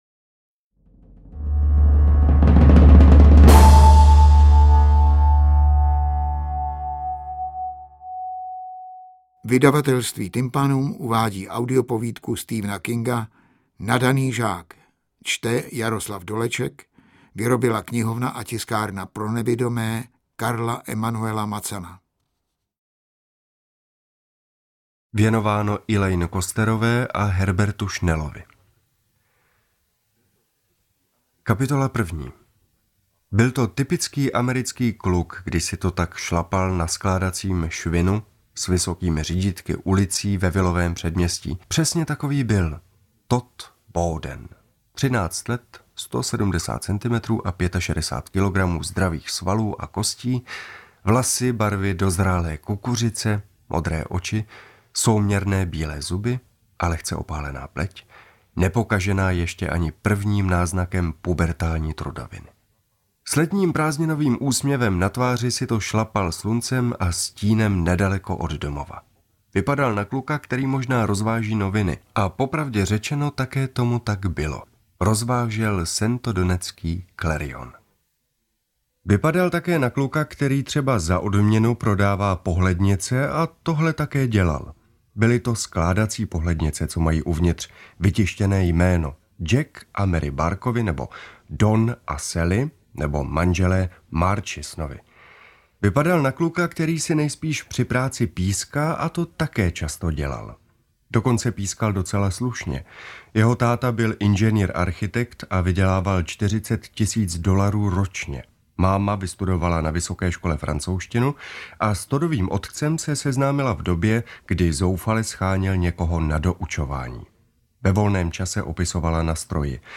Přednes hodnotím za jedna.
AudioKniha ke stažení, 39 x mp3, délka 8 hod. 21 min., velikost 570,9 MB, česky